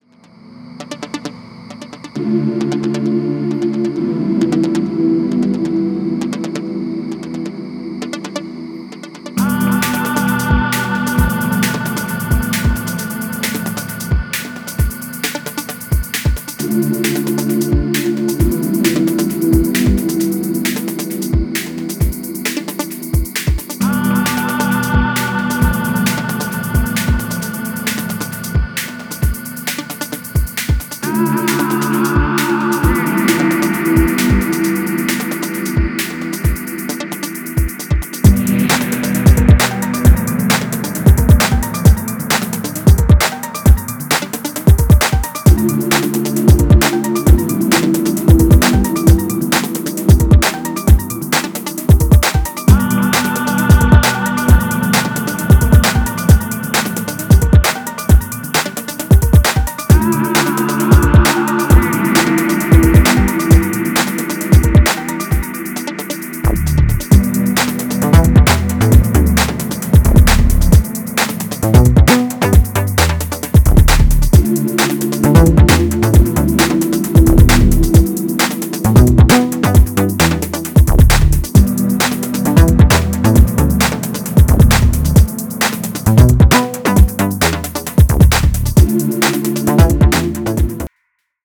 Styl: Dub/Dubstep, Breaks/Breakbeat